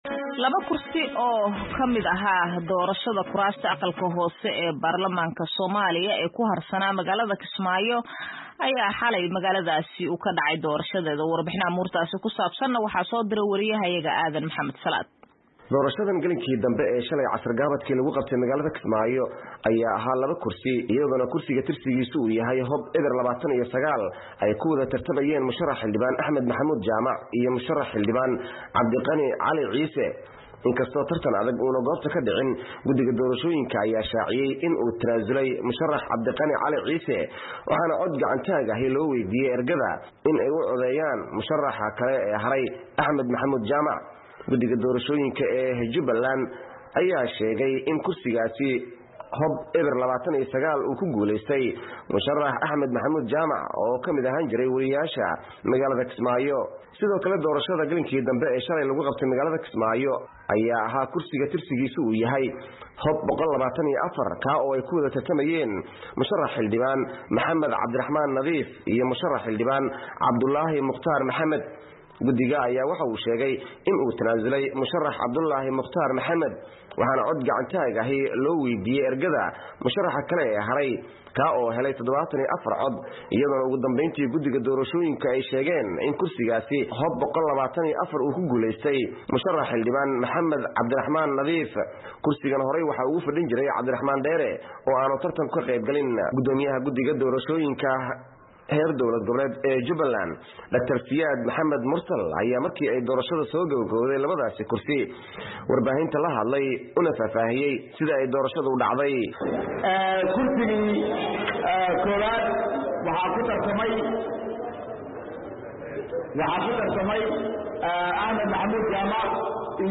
Warbixin ku saabsan doorashada laba kursi oo ka dhacday Kismaayo